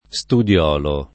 studiolo